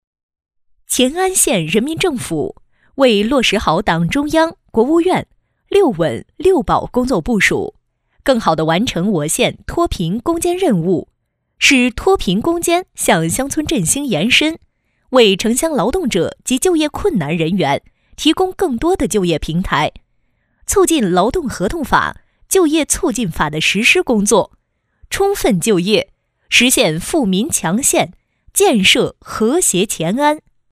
C女47号 | 声腾文化传媒